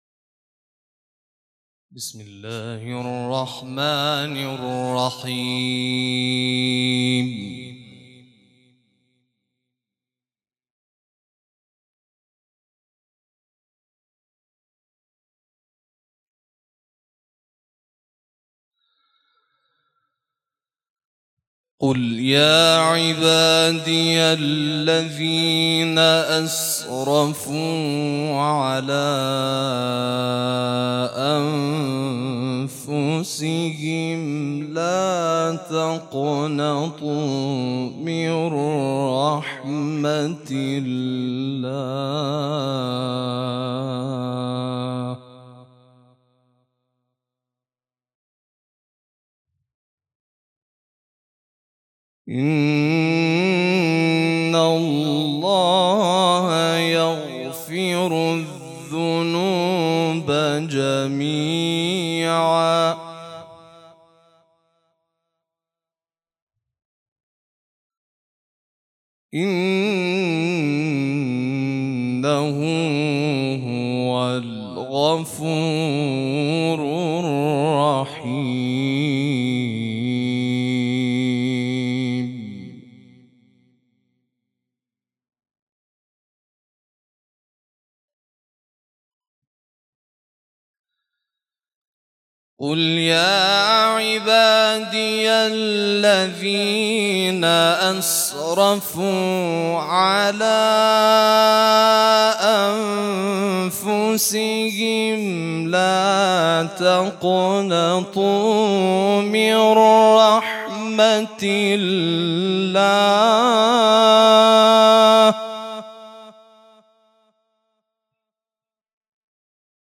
تلاوت مجلسی ، مسجد جامع یزد